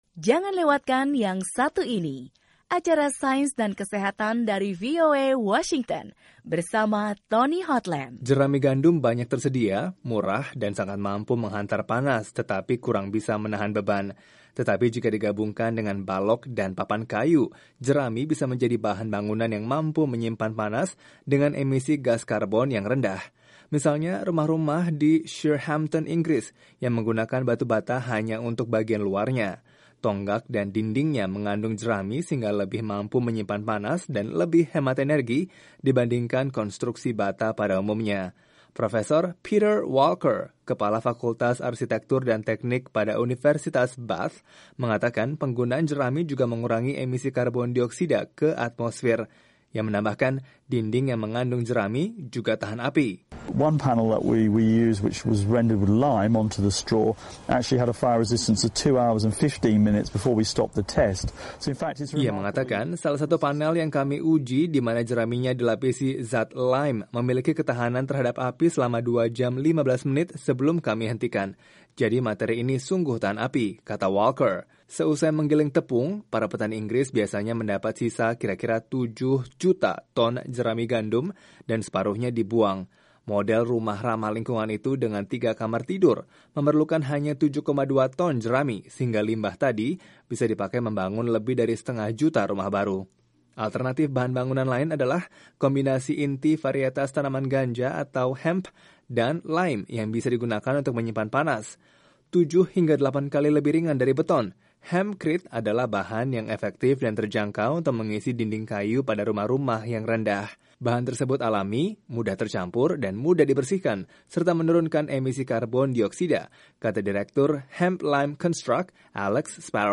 Sejak lama, banyak periset berusaha menggunakan produk-produk sampingan pertanian untuk membuat tempat tinggal yang ramah lingkungan. Baru-baru ini, produsen di Inggris mulai menawarkan rumah yang dibuat dari tumpukan jerami. Ikuti laporannya